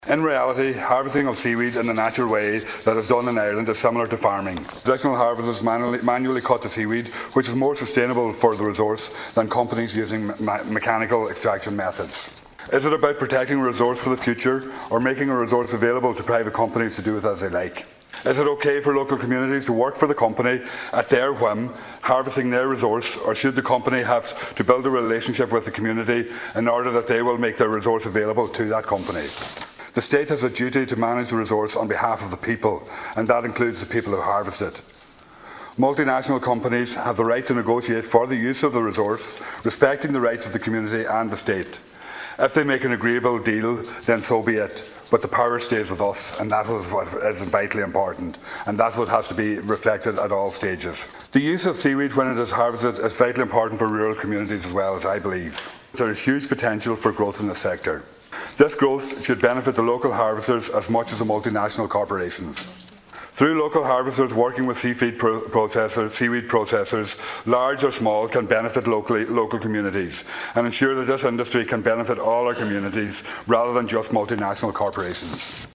However, Deputy Pringle told the Dail while traditional methods are more sustainable, the government seems to be coming down in support of big companies: